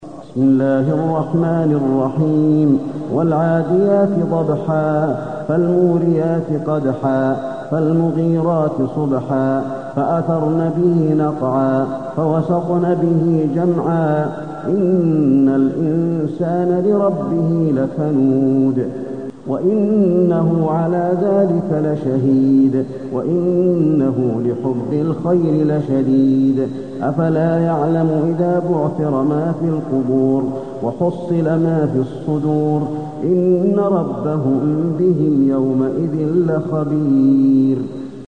المكان: المسجد النبوي العاديات